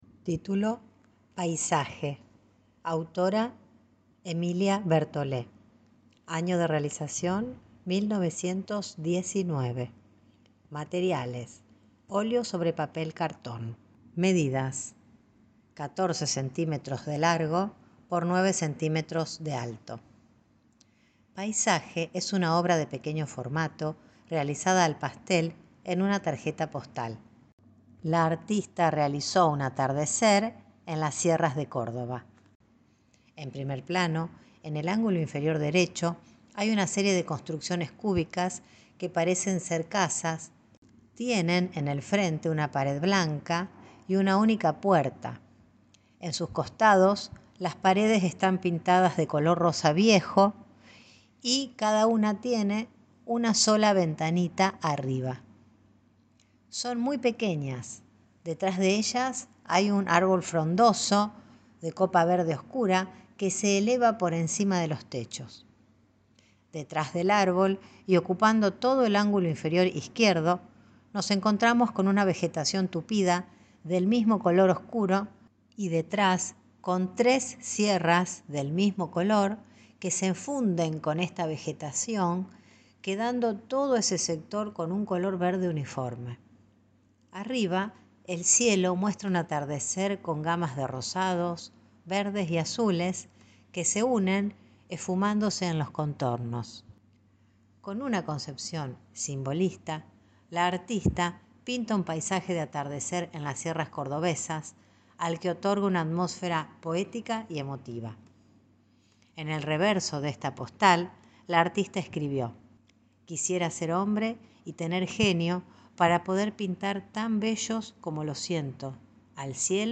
Audiodescripciones y Audioguías